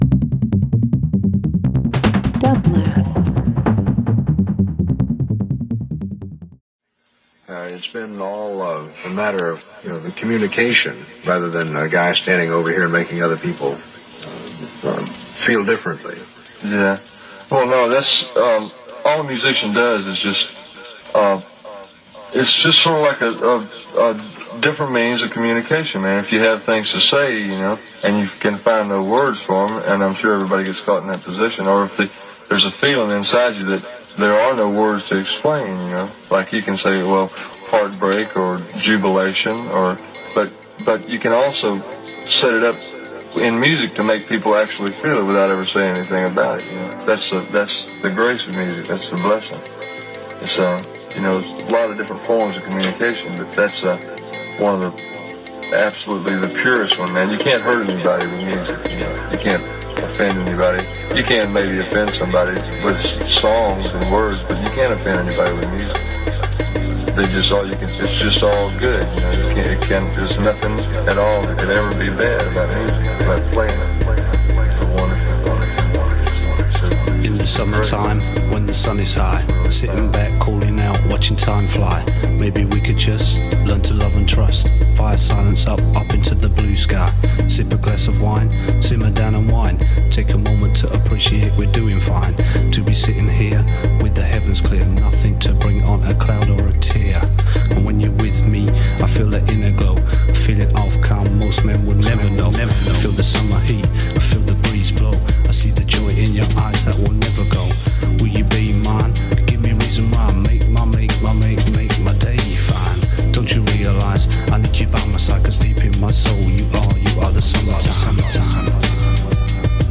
Balearic Beats Downtempo R&B